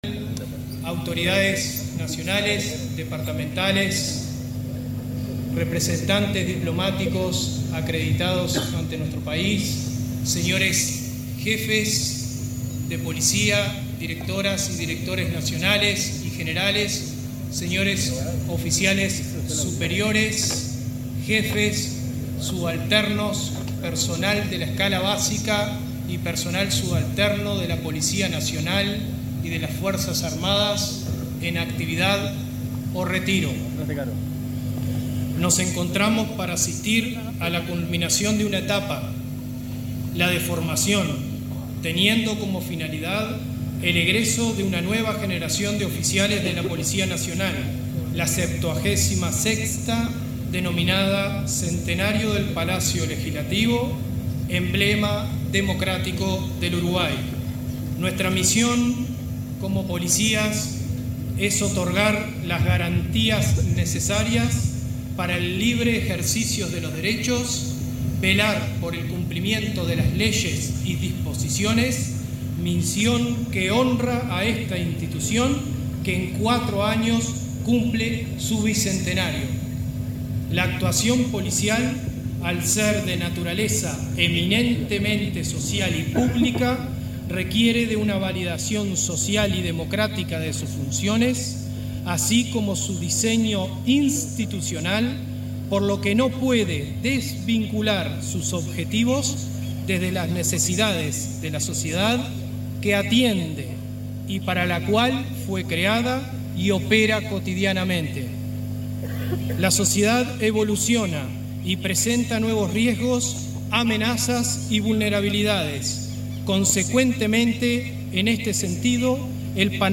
Palabras del director nacional de la Educación Policial, Henry de León
Palabras del director nacional de la Educación Policial, Henry de León 25/11/2025 Compartir Facebook X Copiar enlace WhatsApp LinkedIn Durante la ceremonia de egreso de la Escuela Nacional de Policía, del director nacional de la Educación Policial, Henry de León, hizo uso de la palabra.